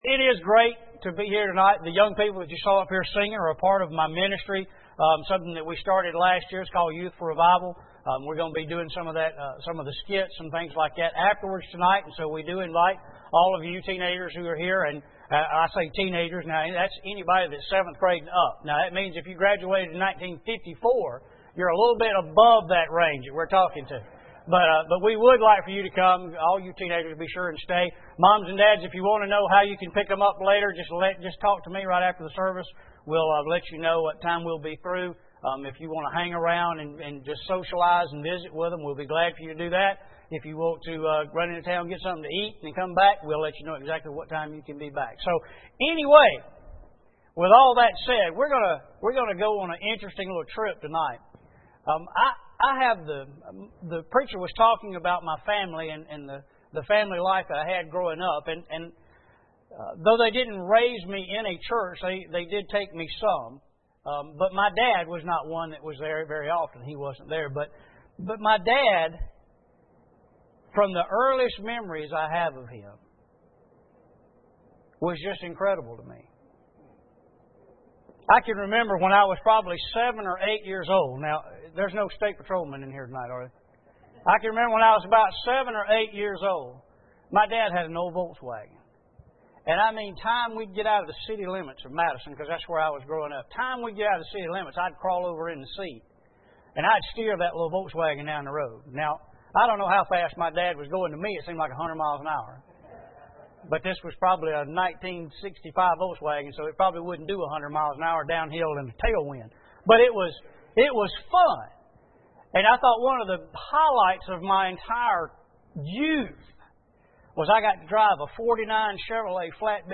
Romans 8:7-17 Service Type: Sunday Evening Bible Text